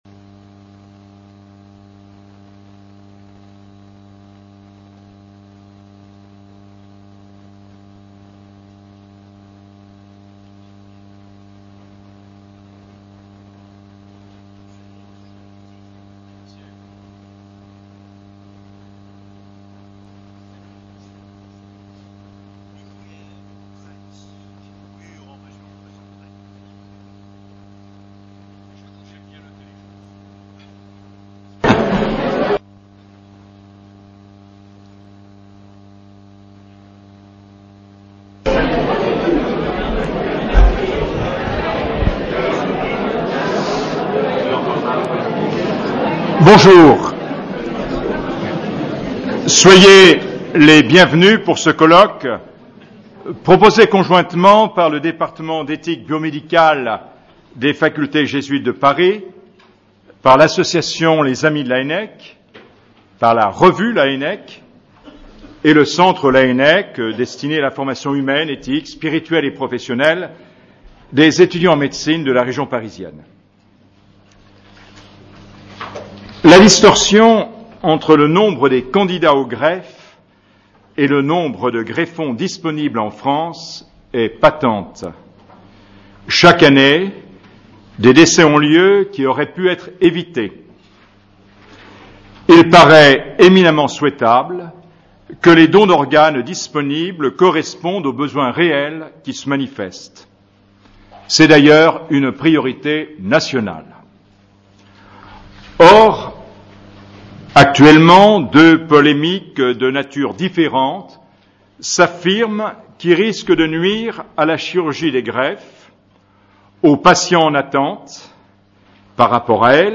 Constat de la mort et consentement Colloque du Département d'éthique Biomédicale Accueil / Audios / Le prélèvement d’organes.